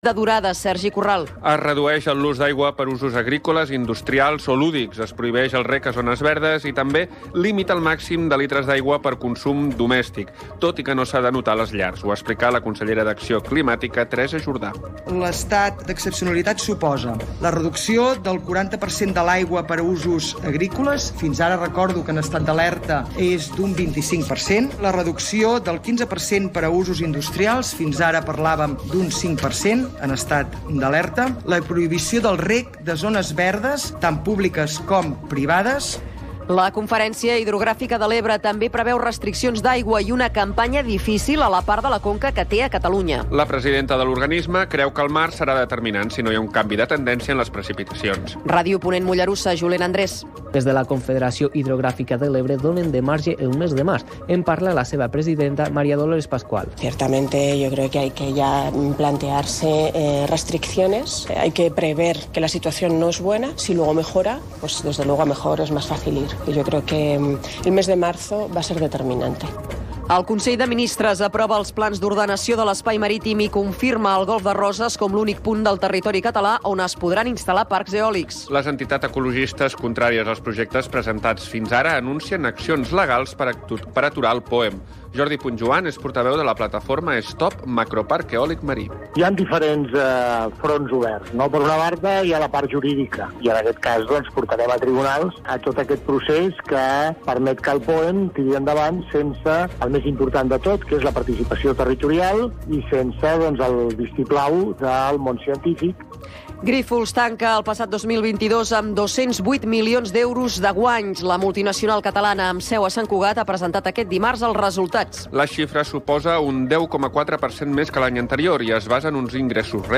Informatius